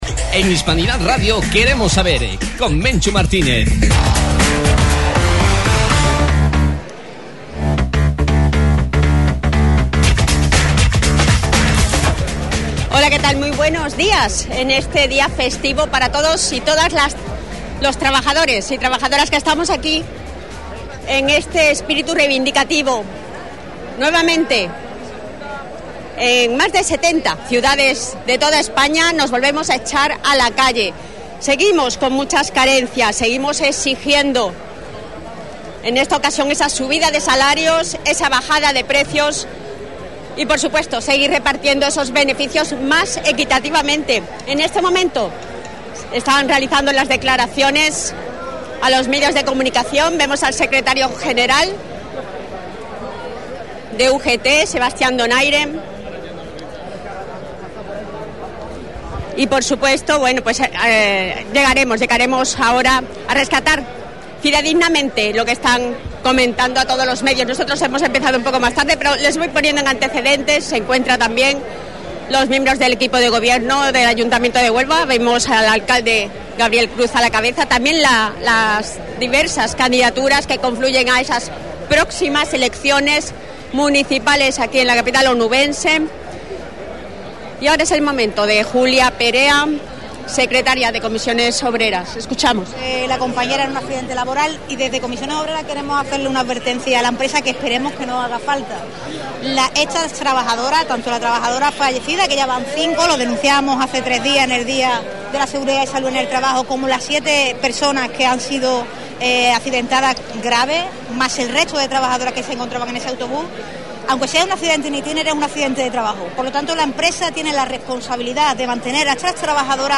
Hoy, Día Internacional de los Trabajadores, jornada festiva y reivindicativa en Huelva. Estuvimos retransmitiendo en directo.